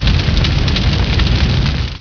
Feuer.wav